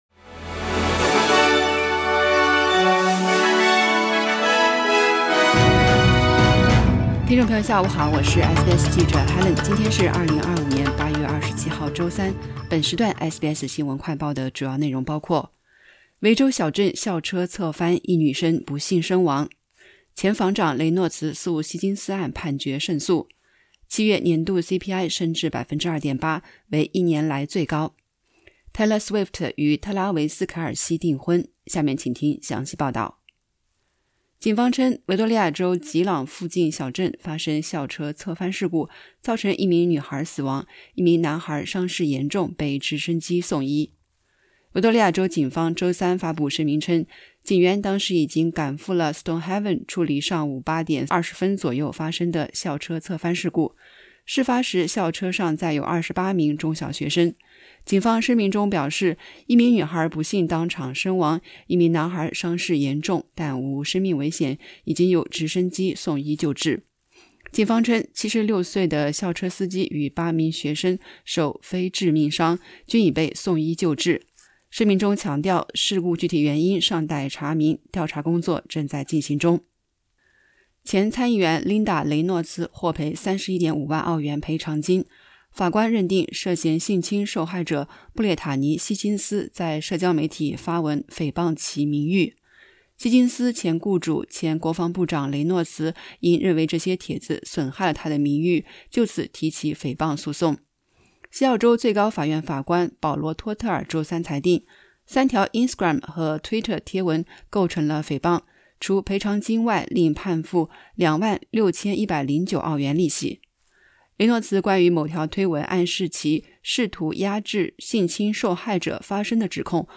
【SBS新闻快报】维州小镇校车侧翻 一女生不幸身亡